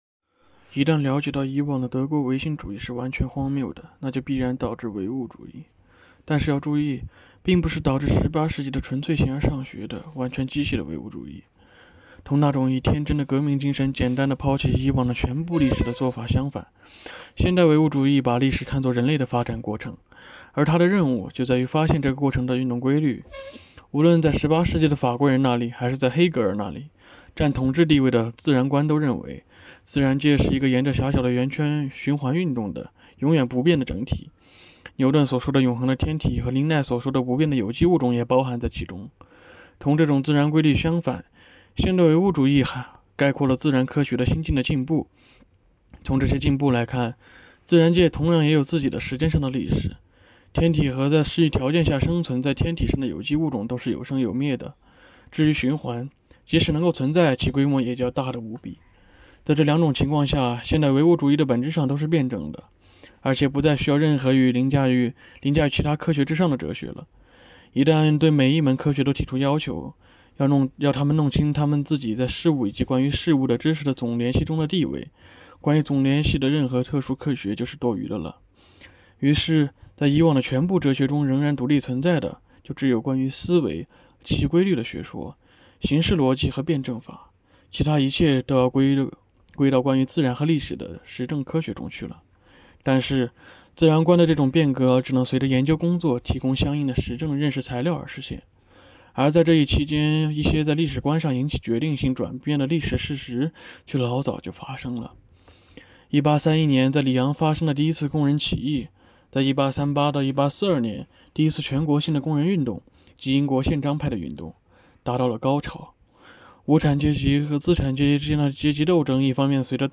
“读经典、悟原理”——2024年西华大学马克思主义经典著作研读会接力诵读（02期）